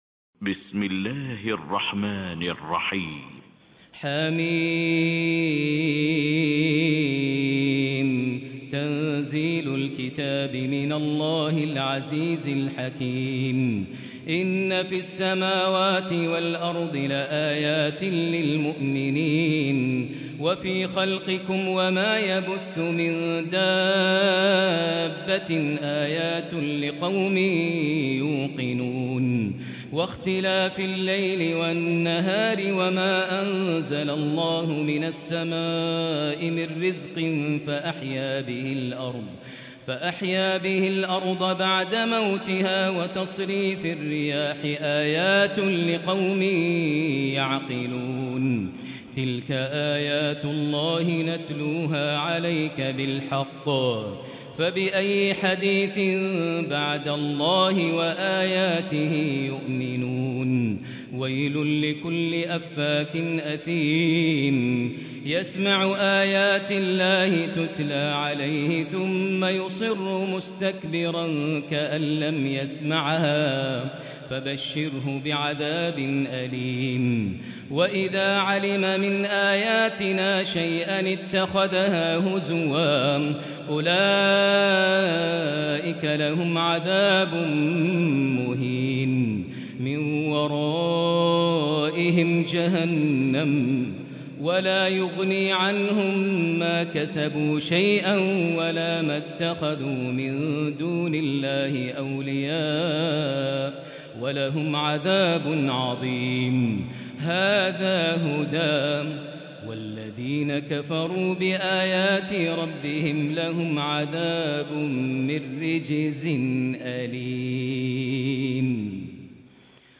Quran recitations
Tarawih prayer from the holy Mosque